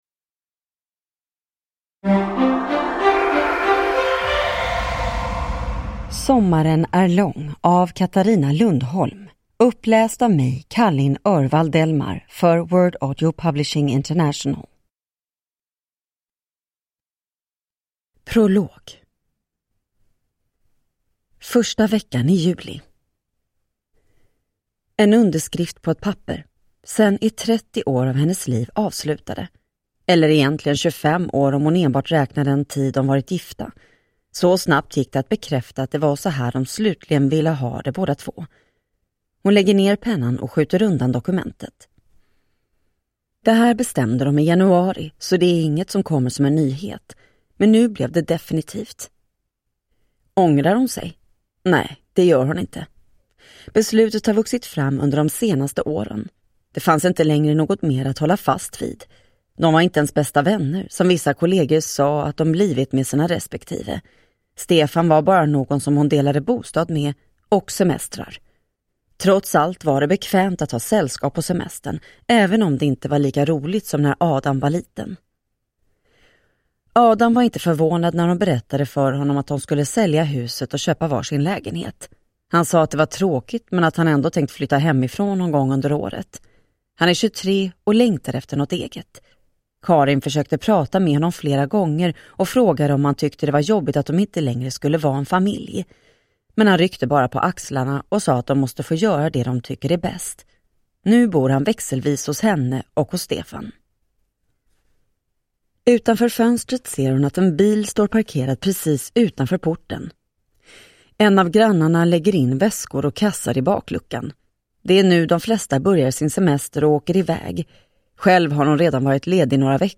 Sommaren är lång (vecka 29) (ljudbok) av Katarina Lundholm